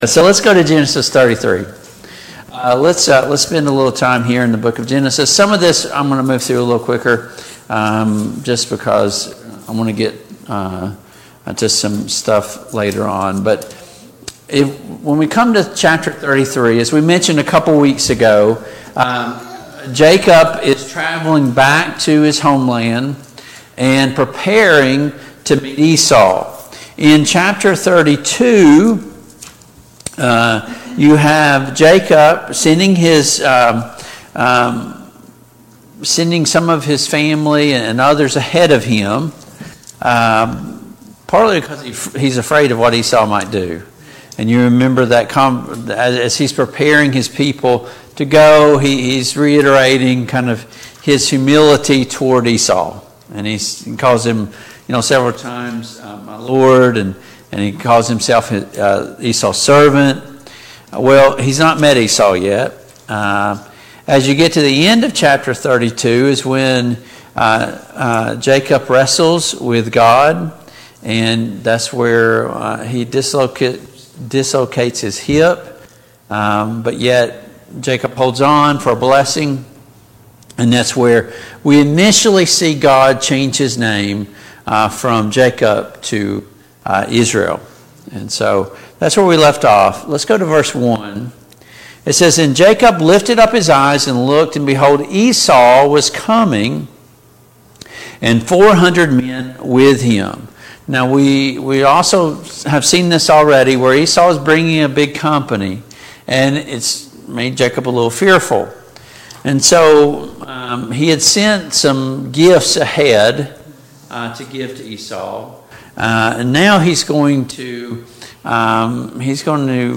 Study of Genesis Passage: Genesis 33, Genesis 34 Service Type: Family Bible Hour Topics